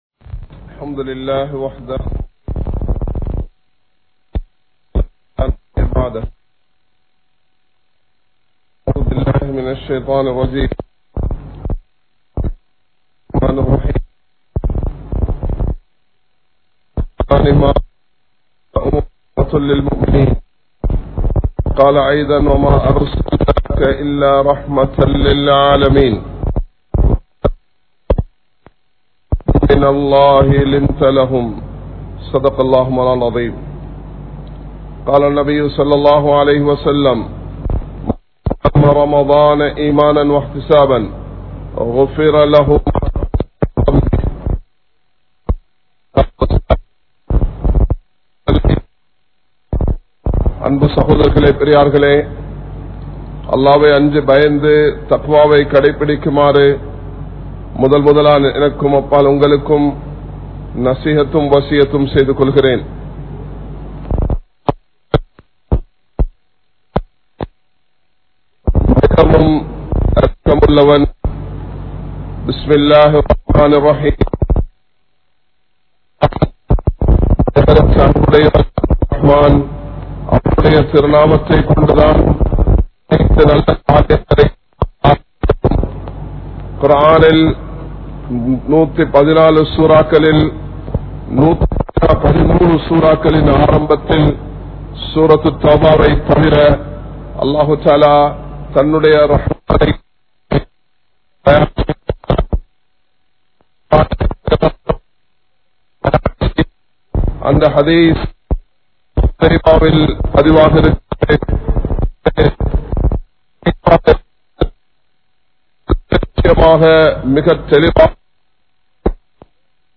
Allahvin Rahmath Ungalukku Veanduma? (அல்லாஹ்வின் றஹ்மத் உங்களுக்கு வேண்டுமா?) | Audio Bayans | All Ceylon Muslim Youth Community | Addalaichenai
Colombo 03, Kollupitty Jumua Masjith